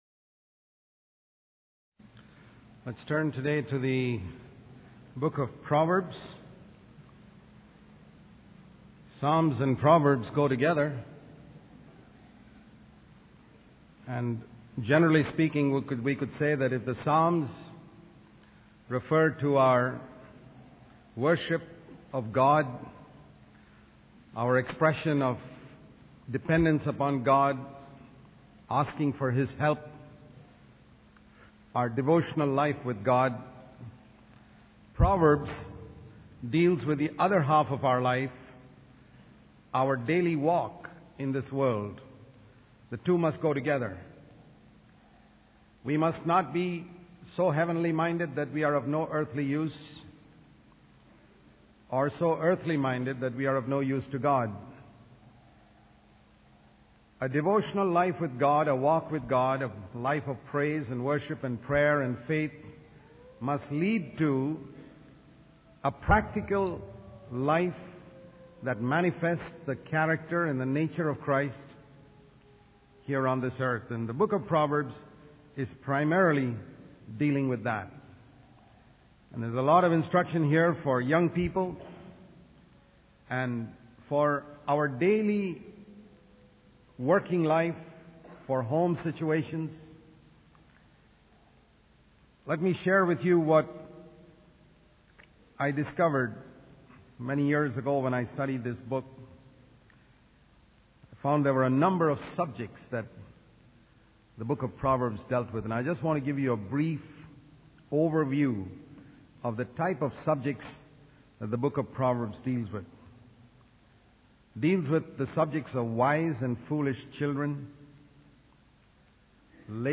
In this sermon, the speaker emphasizes the importance of being wise and disciplined in various aspects of life. He warns against engaging in immoral relationships and encourages young people to be cautious in their friendships with the opposite sex.